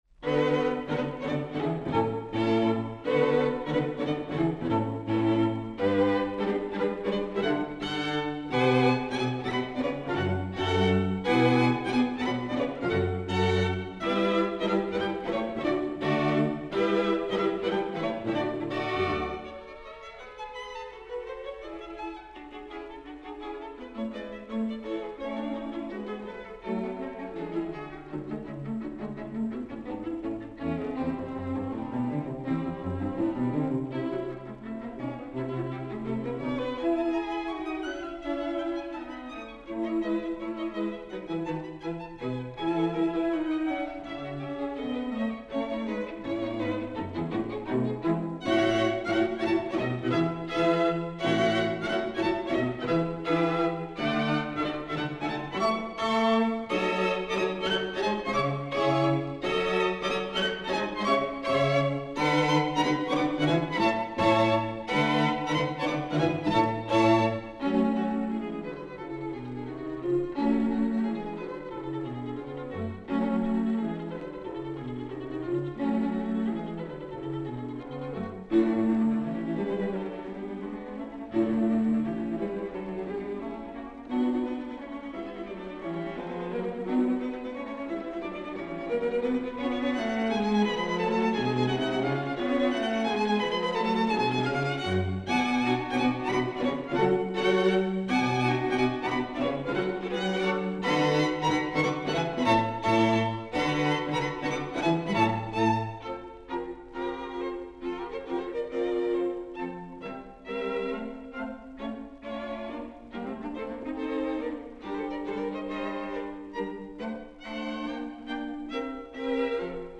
violin
viola
cello
SCHUMANN-QUATUOR-OP.-41-n°-3-Finale.mp3